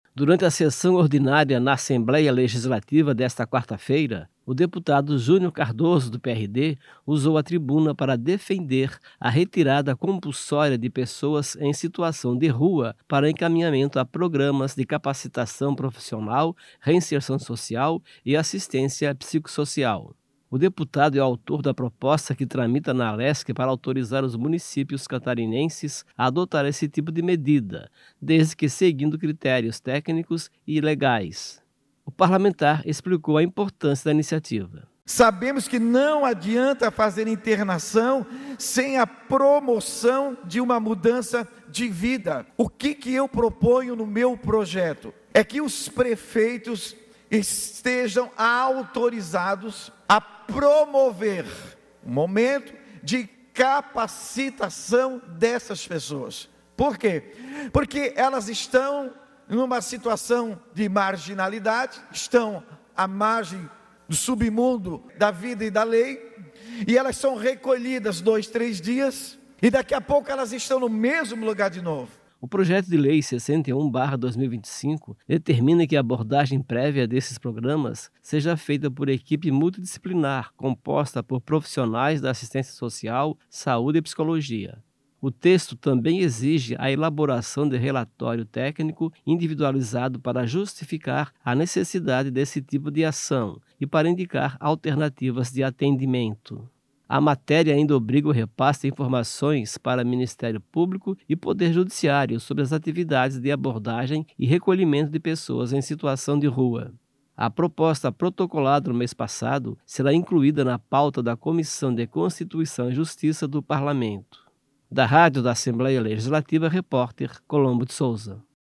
Durante a sessão ordinária na Assembleia Legislativa desta quarta-feira (12), o deputado Junior Cardoso (PRD) usou a tribuna para defender a retirada compulsória de pessoas em situação de rua para encaminhamento a programas de capacitação profissional, reinserção social e assistência psicossocial.
Entrevista com:
- deputado Junior Cardoso (PRD).